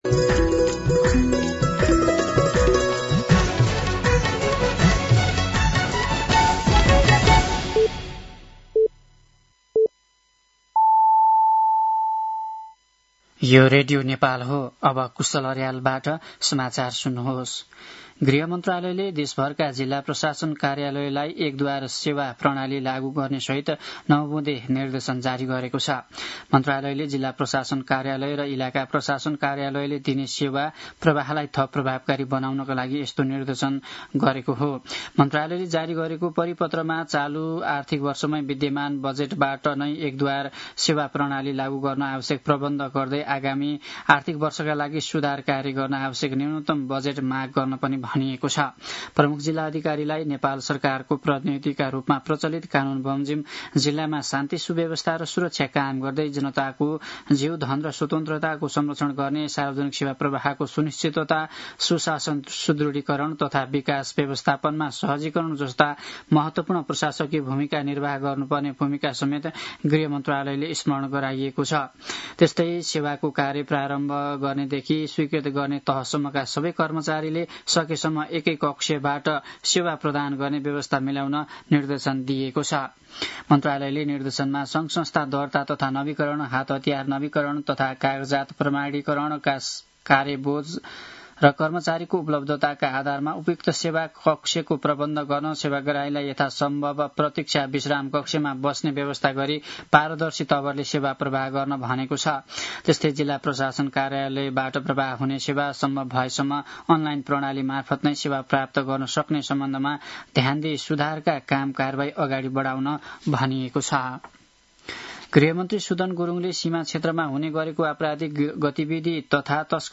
साँझ ५ बजेको नेपाली समाचार : ५ वैशाख , २०८३
5-pm-nepali-news.mp3